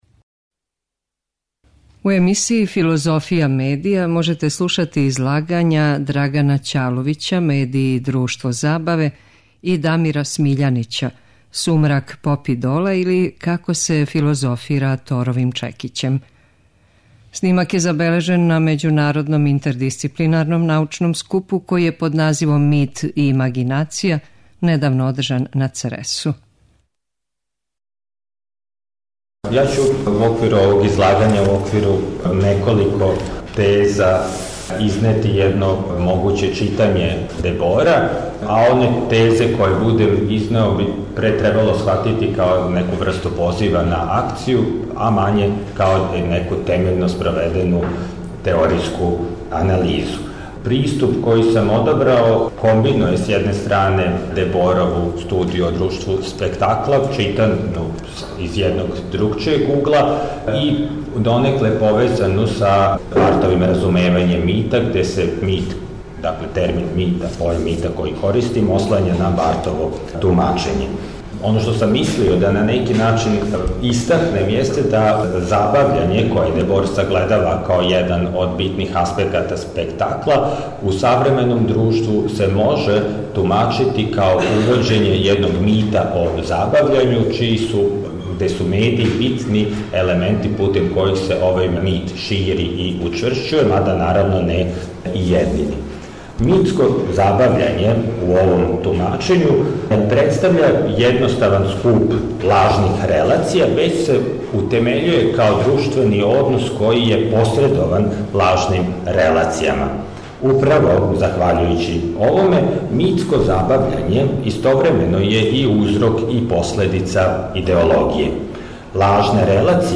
Снимак је забележен на Међународном интердисциплинарном научном скупу који је под називом „Мит и имагинација” недавно одржан на Цресу.